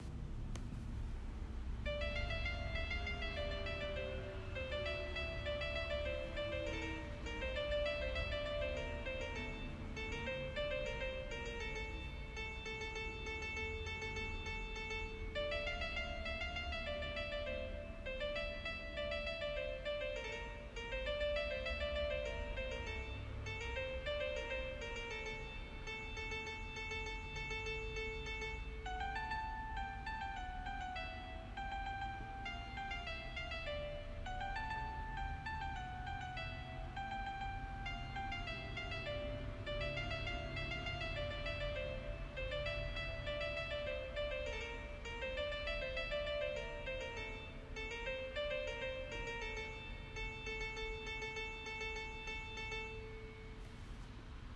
Nota Ses Dosyası – HIZLANDIRILMIŞTIR.